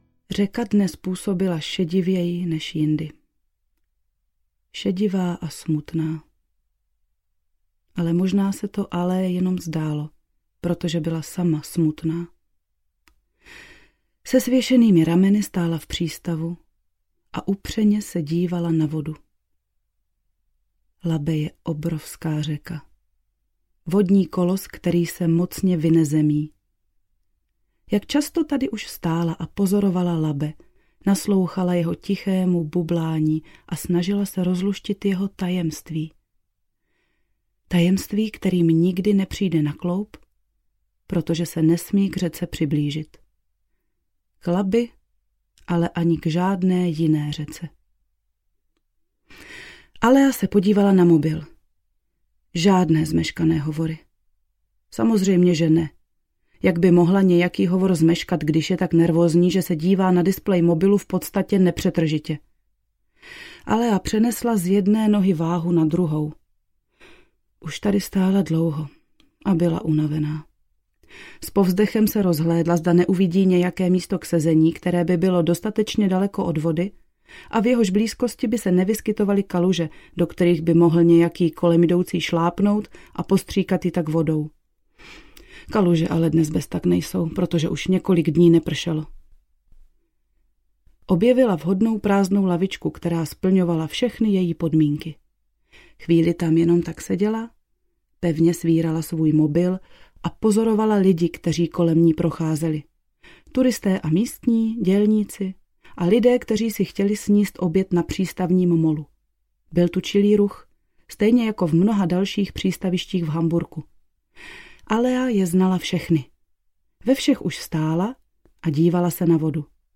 Alea - dívka moře: Volání z hlubin audiokniha
Ukázka z knihy
alea-divka-more-volani-z-hlubin-audiokniha